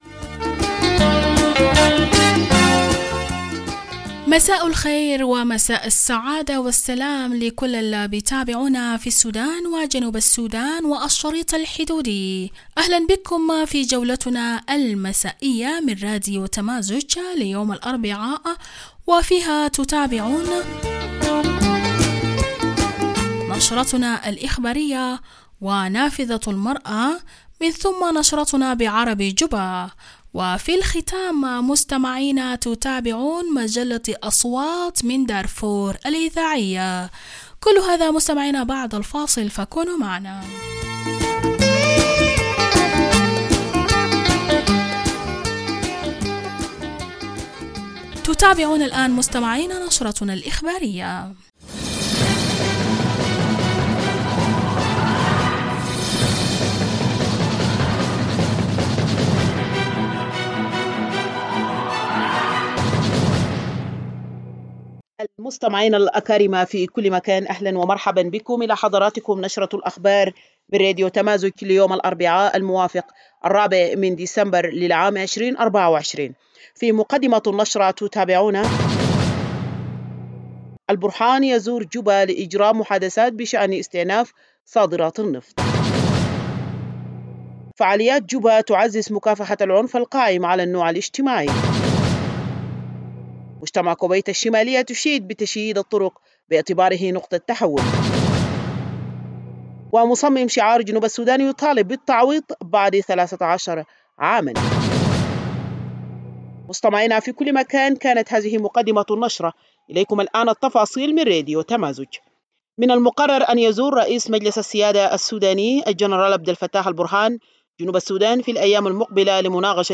Evening Broadcast 04 December - Radio Tamazuj